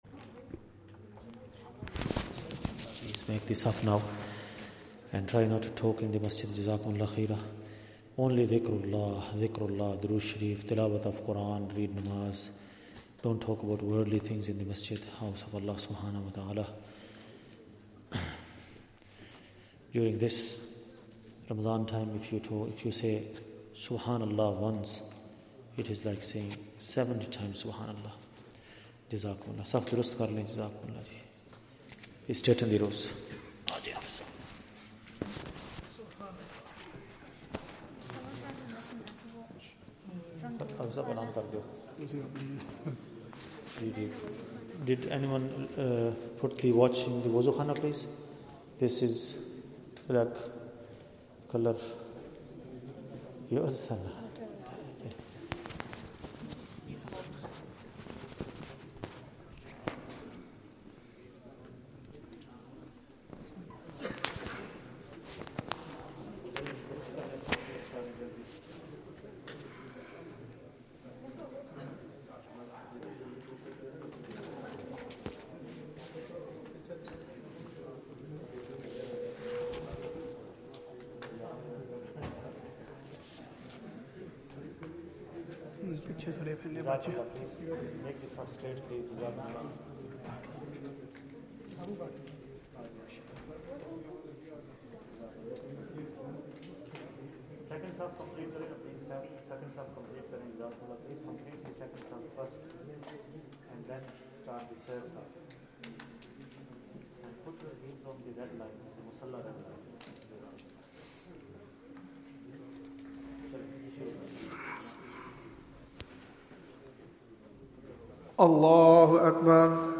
Zakariyya Masjid Motherwell | Taraweeh | eMasjid Live
Taraweeh